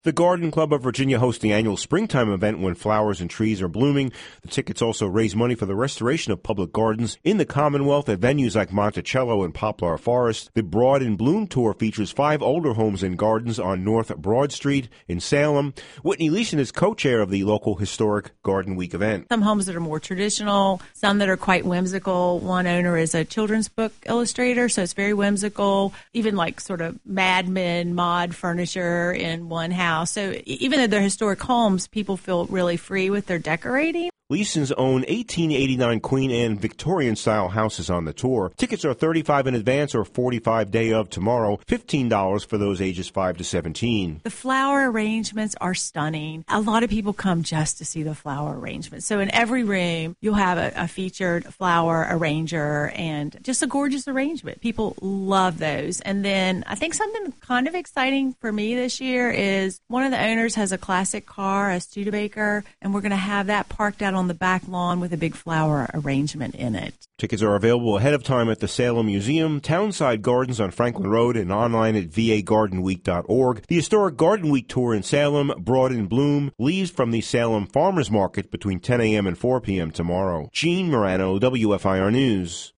The story